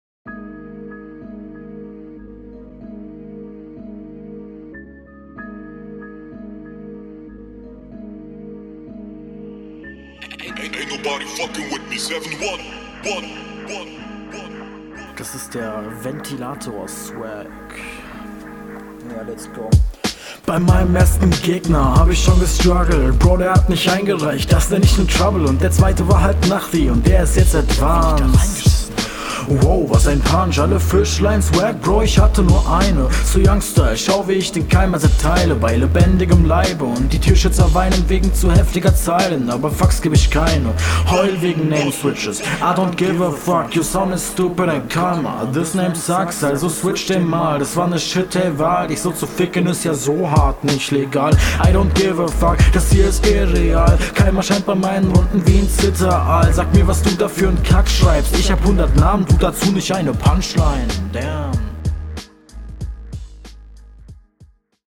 mix bisi komisch. höhen stechen zwar nicht aber stimme ist schon etwas zu laut. aber …
Deine mische eindeutig besser genauso wie dein Flow und deine Betonungen.